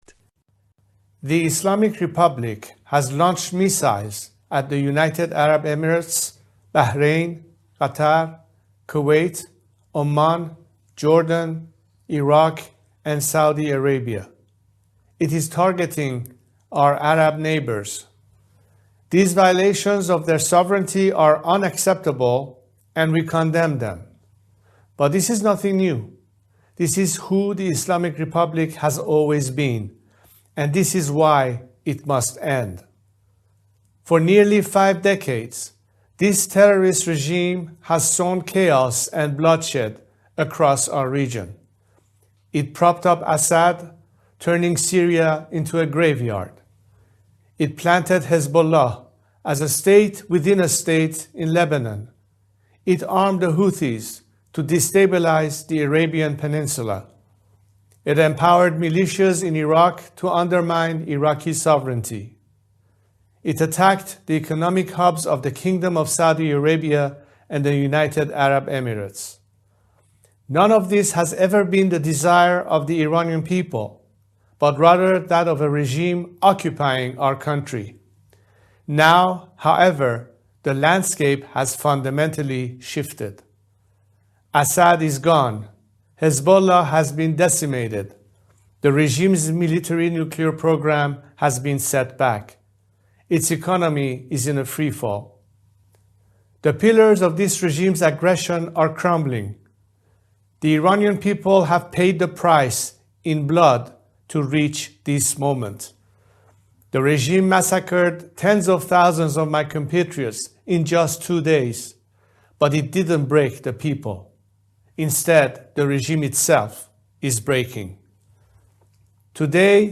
Date: October 2, 2025 / UTC: 1830-1930 / Frequency: 15540 Khz.
Rare On-Air Announcement in English - March 12, 2026